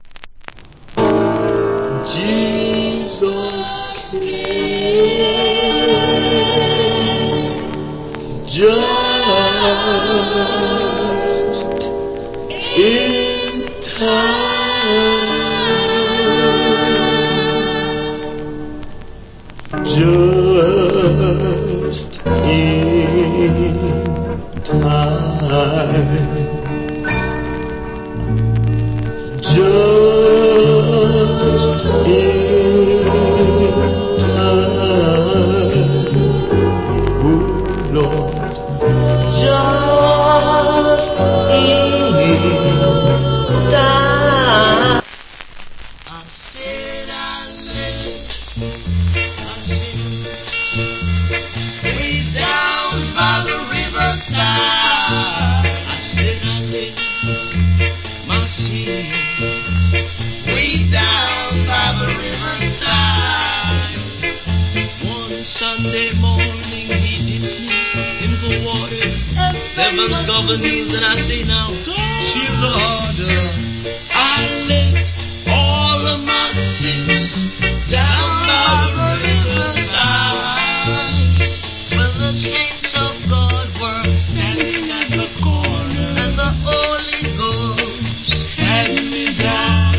Nice Gospel Vocal.